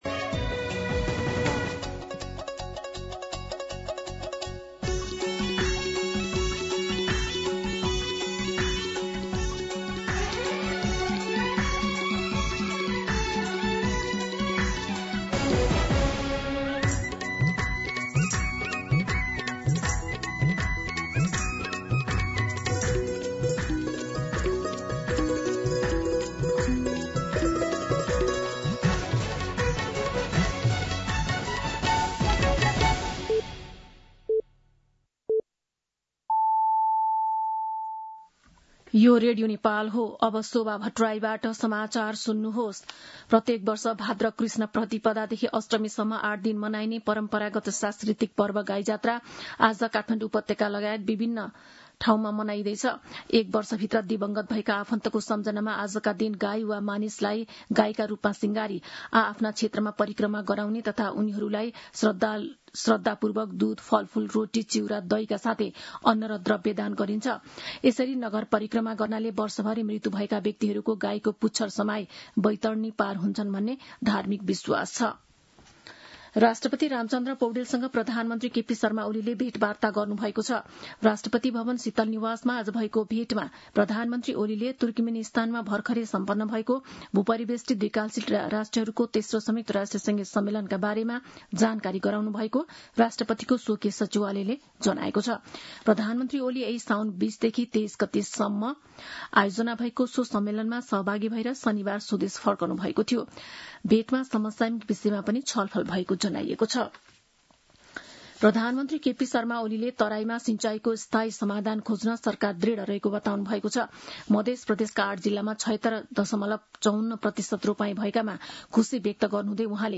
दिउँसो ४ बजेको नेपाली समाचार : २५ साउन , २०८२
4-pm-Nepali-News-3.mp3